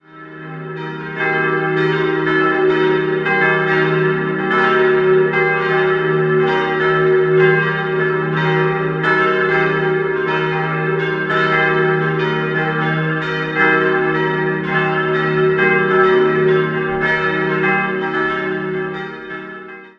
Seit dem Marianischen Jahr 1987 erklingt das "Salve-Regina" vom Turm, denn in diesem Jahr wurde die große Marienglocke, welche bei Petit&Fritsen in Aarle-Rixtel (Niederlande) gegossen wurde, ergänzt. Außerdem befindet sich im Turm noch eine Totenglocke und ein zwölfstimmiges Glockenspiel.